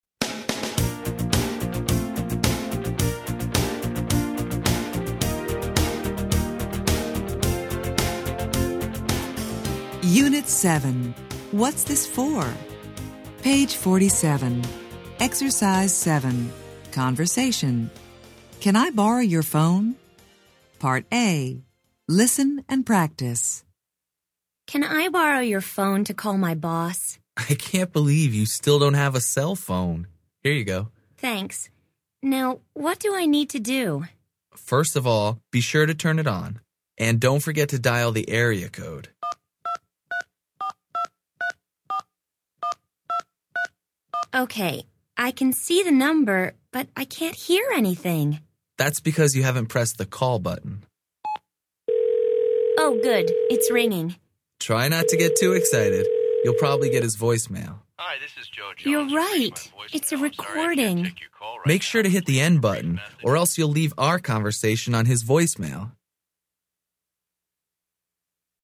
Interchange Third Edition Level 2 Unit 7 Ex 7 Conversation Track 13 Students Book Student Arcade Self Study Audio